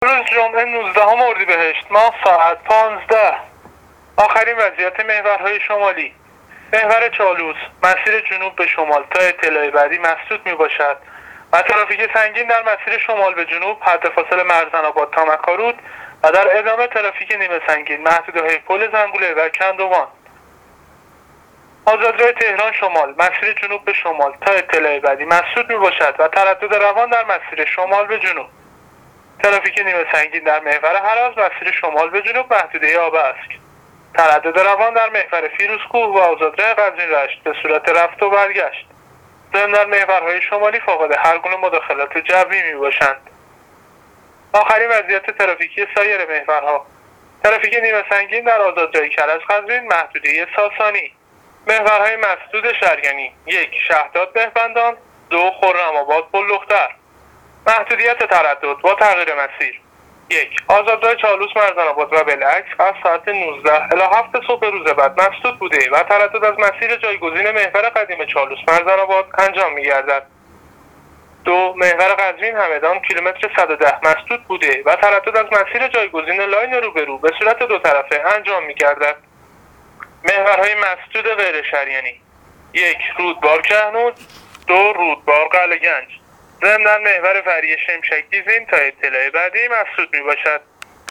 گزارش رادیو اینترنتی از آخرین وضعیت ترافیکی جاده‌ها تا ساعت ۱۵ نوزدهم اردیبهشت ۱۳۹۹